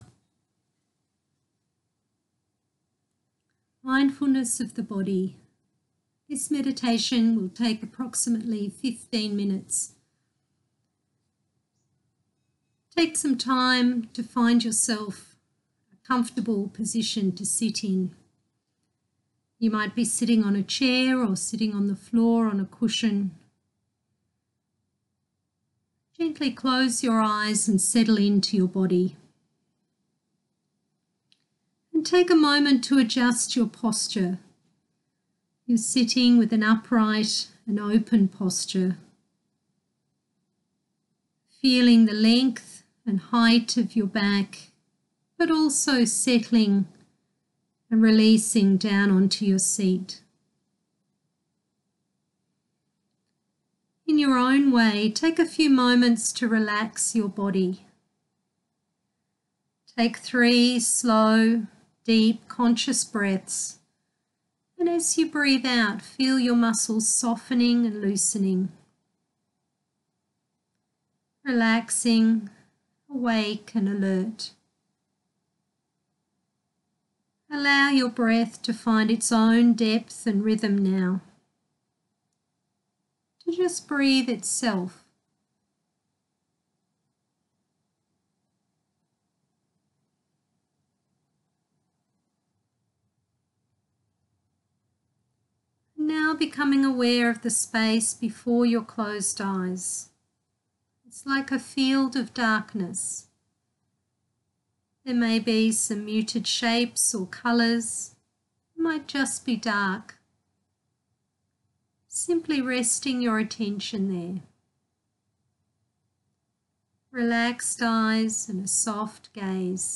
mindfulness-of-body-15-mins.m4a